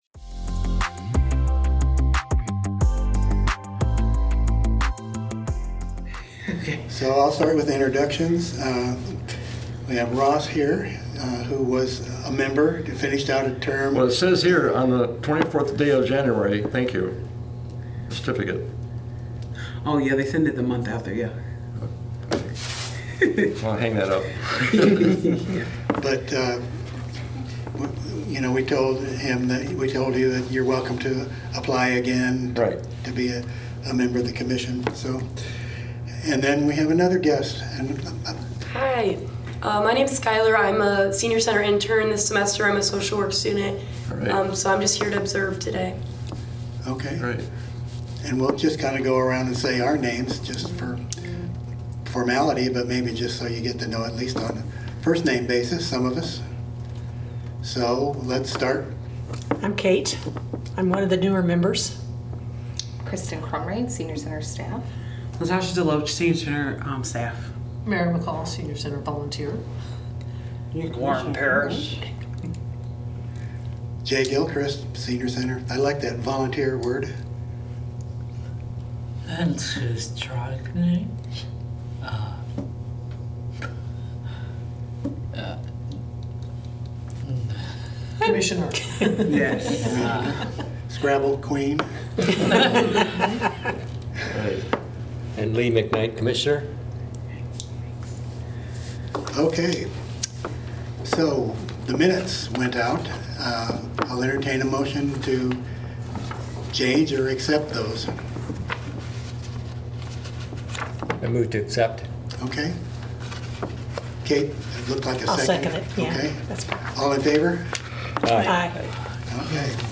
Regular monthly meeting of the Senior Center Commission.